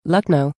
Lucknow (/ˈlʌkn/
En-Lucknow.ogg.mp3